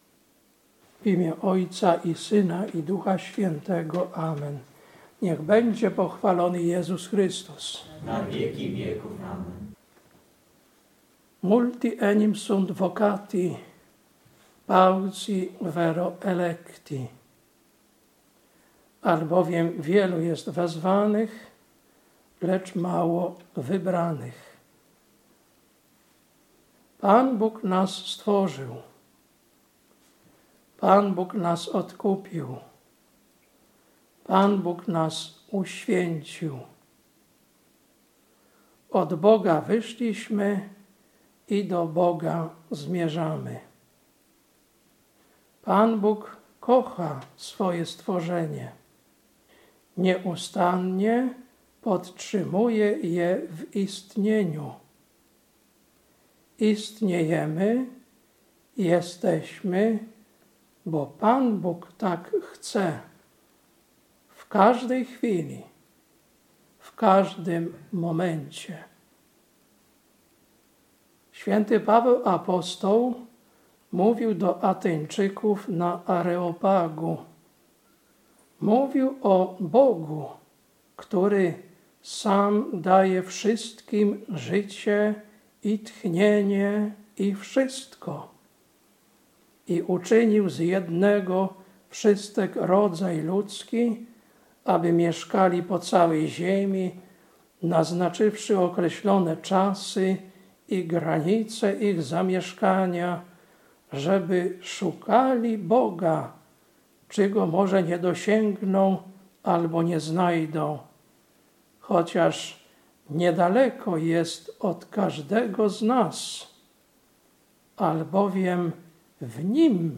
Kazanie na XIX Niedzielę po Zesłaniu Ducha Świętego, 19.10.2025 Ewangelia: Mt 22, 1-14